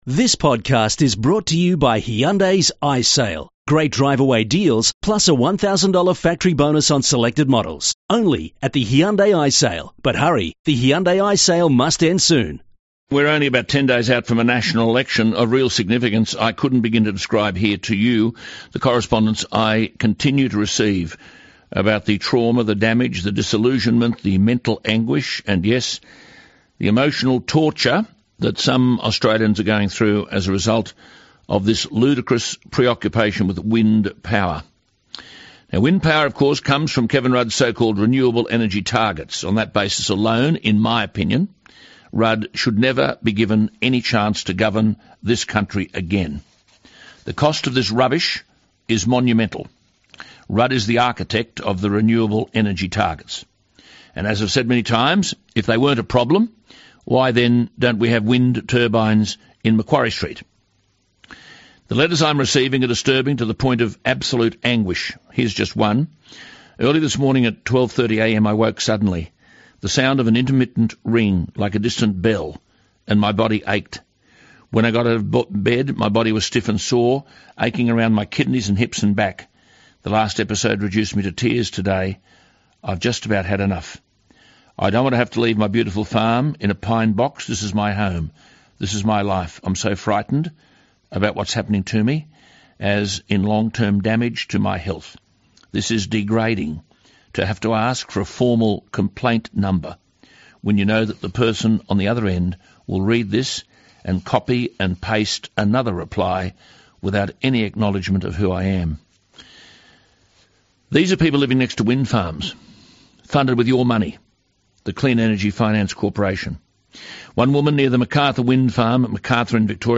Chris Booker was interviewed by Alan Jones this morning. Alan has a little radio show that more than just a few Australians tune into each morning.